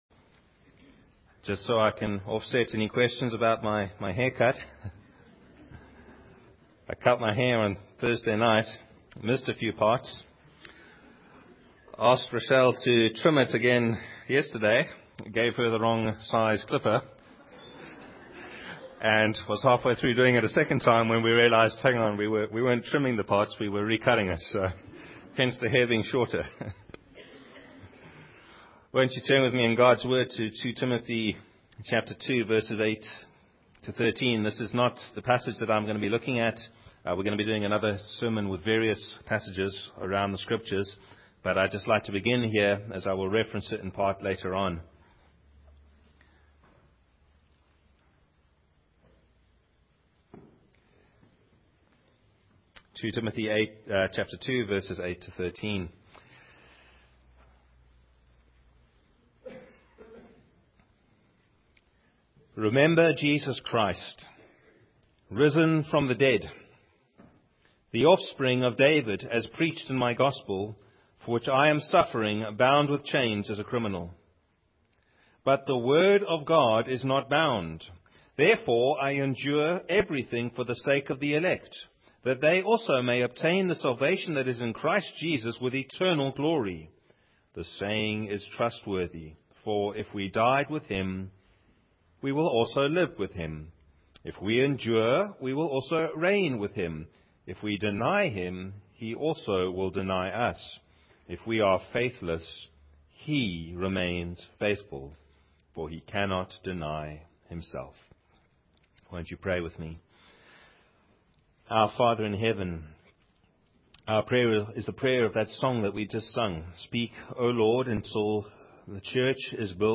You can download the sermon notes here –>> Mission Hope